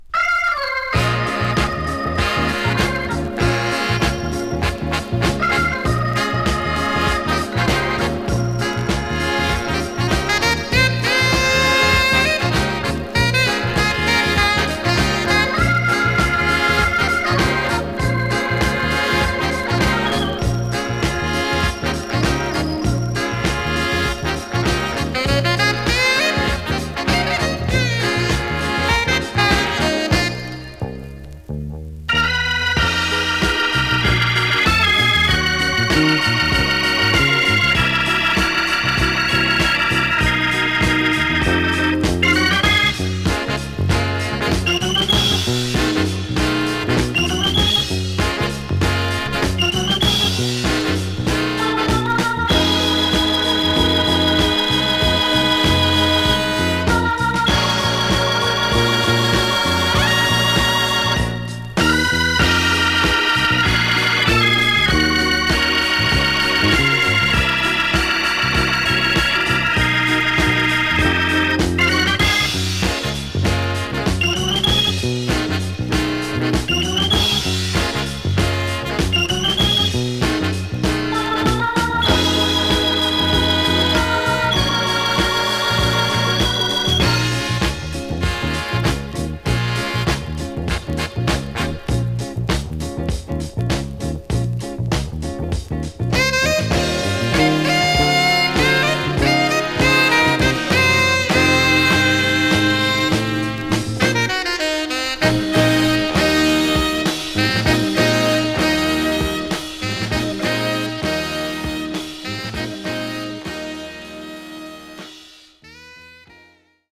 3. > JAZZ FUNK/RARE GROOVE